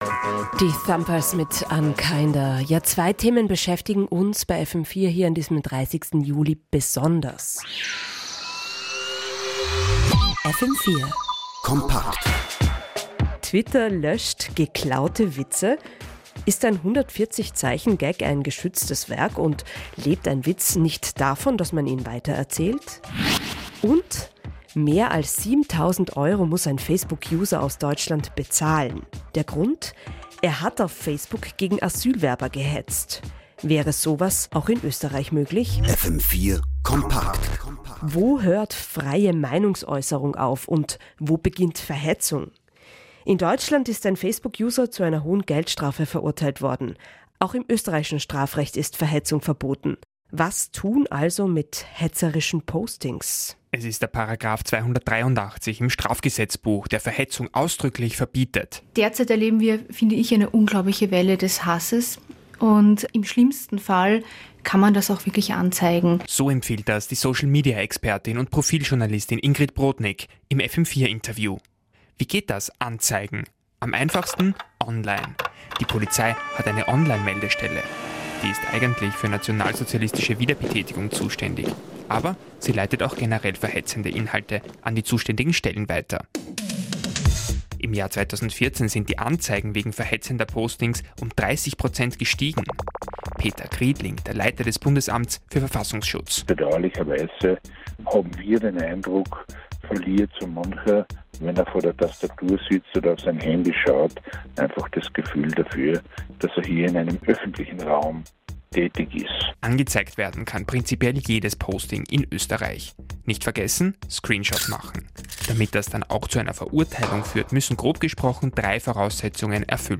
FM4-Interview: „Twitter“ und Urheberrecht
Mitschnitt der Sendung „kompakt“ ORF/Radio FM4 vom  30.7.2015: